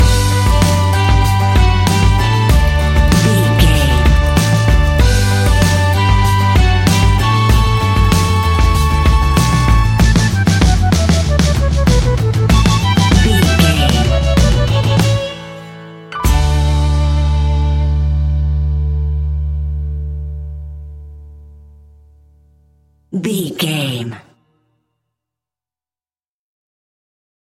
Uplifting
Ionian/Major
D
acoustic guitar
mandolin
ukulele
lapsteel
drums
double bass
accordion